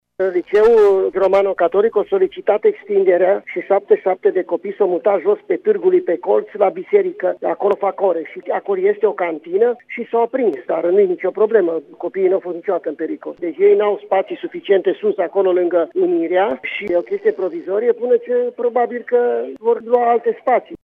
Inspectorul școlar general al județului Mureș, Ioan Macarie, a explicat pentru Radio Tg.Mureș că în clădirea în care a izbucnit incendiul se țineau și cursuri pentru că Liceul Romano-catolic nu are spații suficiente de învățământ: